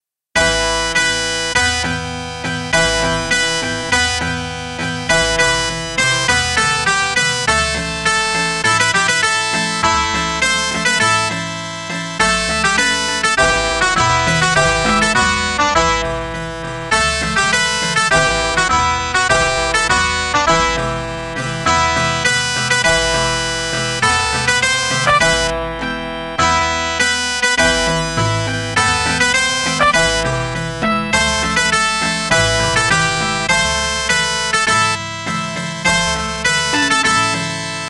トルコ軍隊行進曲。